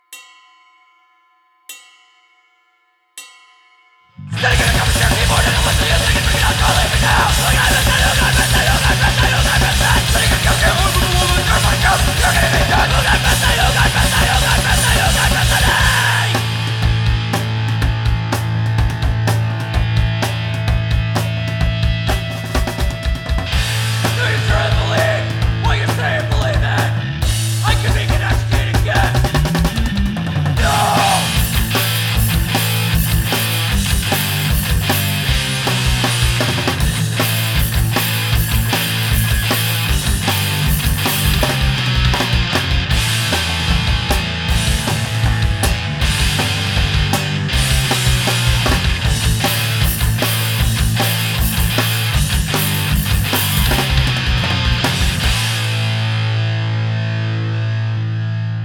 Loud and heavy music
Punk Rock Music